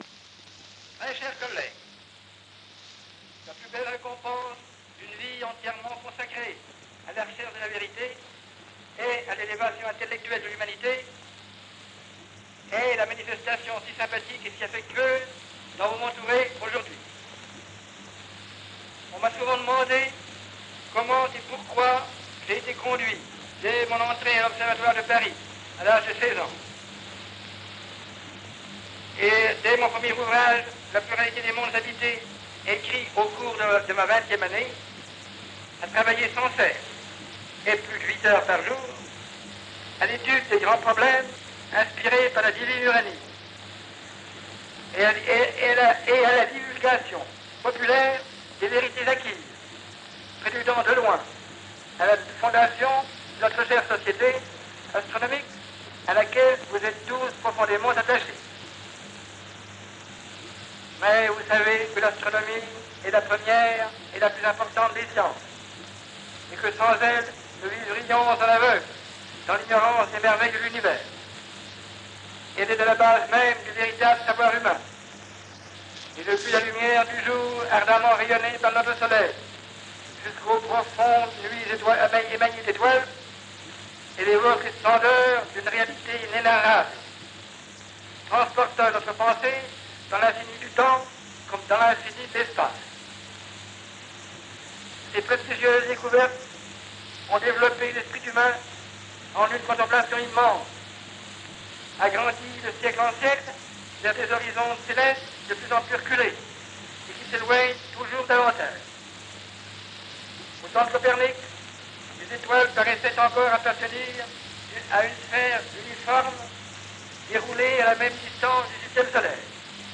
La voix de Camille Flammarion
Allocution à l’occasion de la célébration de son 80ème anniversaire (14 juin 1922, la Sorbonne).
Camille-Flammarion-Sorbonne-1922.mp3